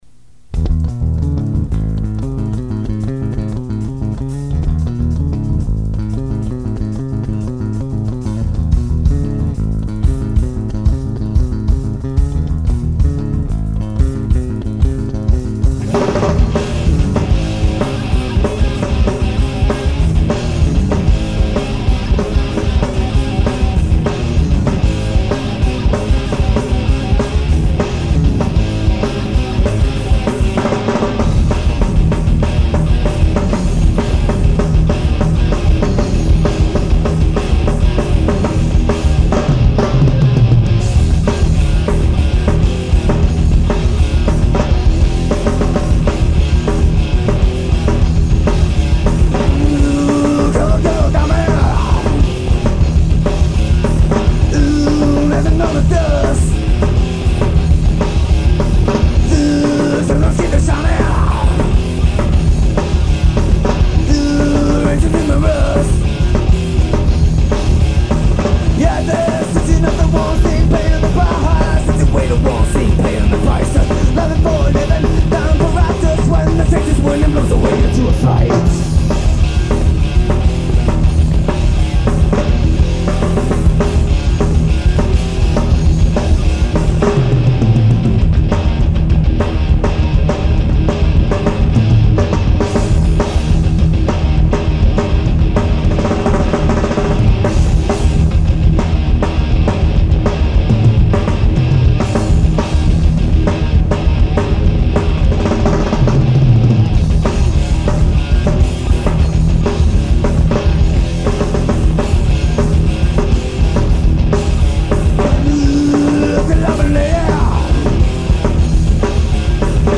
Guitar and Vocals
Bass
Drums